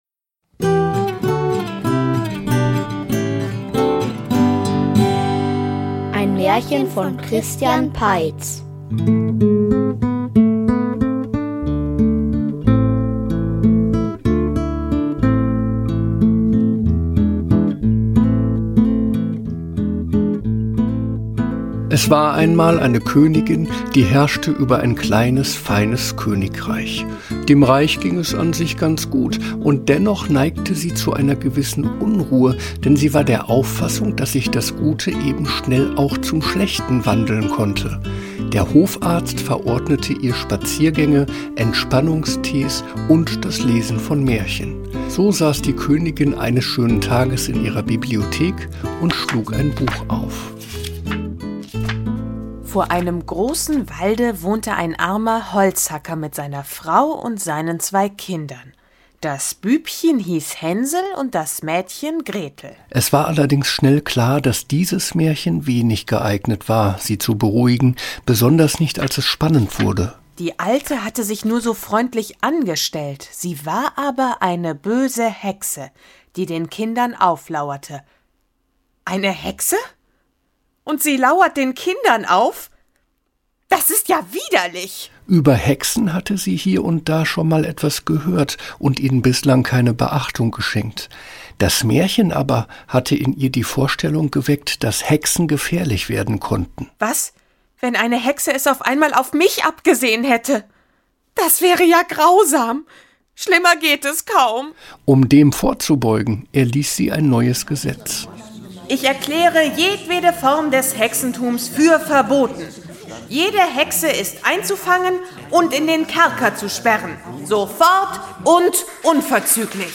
Hexenjagd und Süßgebäck --- Märchenhörspiel #46 ~ Märchen-Hörspiele Podcast